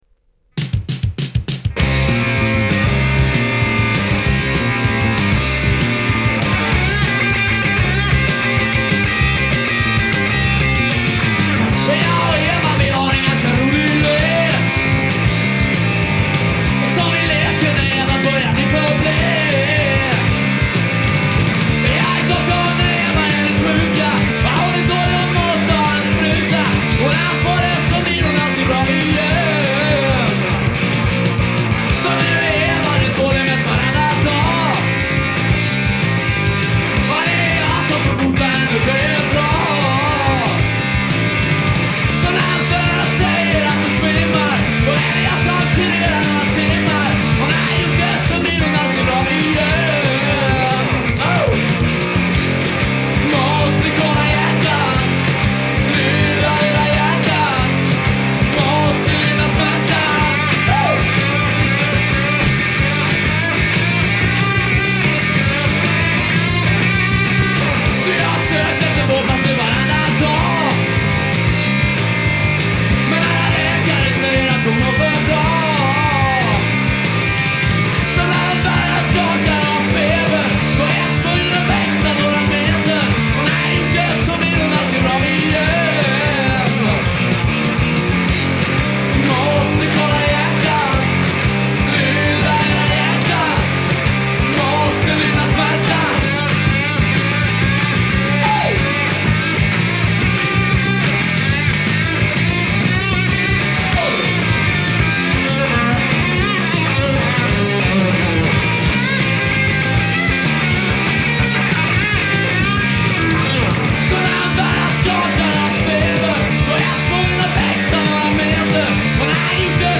Jag har skrivit om det tidigare - svensk pop.
Glamrock med popmelodier